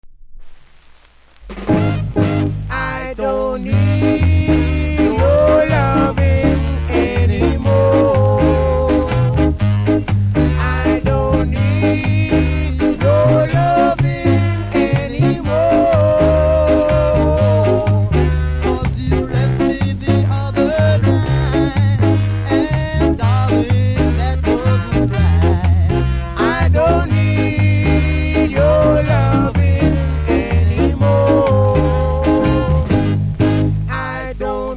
センターずれありますがプレイは問題ないレベルなので試聴で確認下さい。